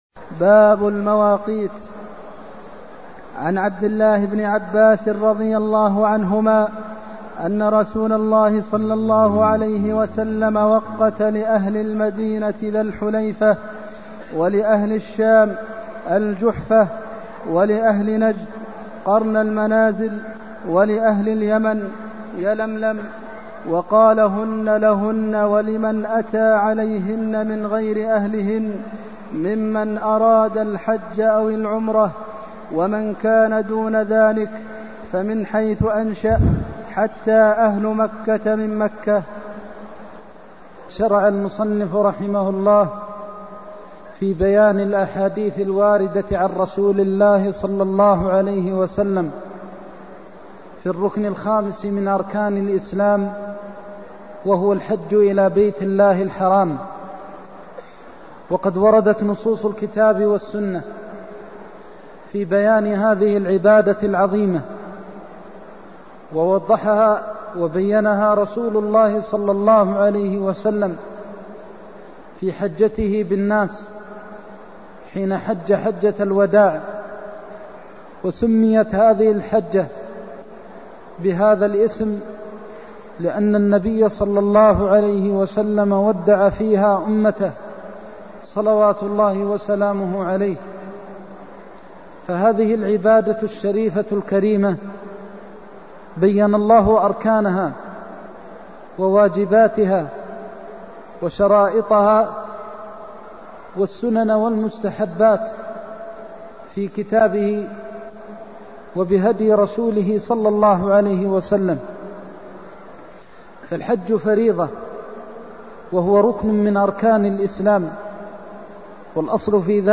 المكان: المسجد النبوي الشيخ: فضيلة الشيخ د. محمد بن محمد المختار فضيلة الشيخ د. محمد بن محمد المختار وقت لأهل المدينة ذي الحليفة (202)